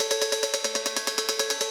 Index of /musicradar/shimmer-and-sparkle-samples/140bpm
SaS_Arp01_140-A.wav